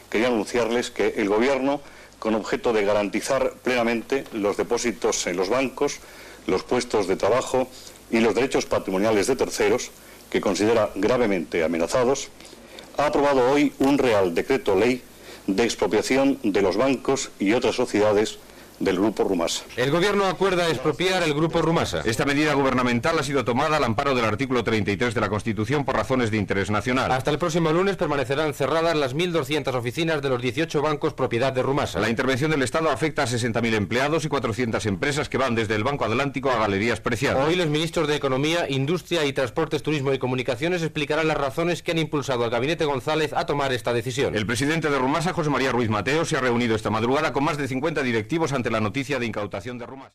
Eduardo Sotillos, portaveu del primer govern socialista de Felipe González, anuncia la decisió d'expropiar el Grup Rumasa. Resum informatiu sobre la qüestió el dia següent de la decisió
Informatiu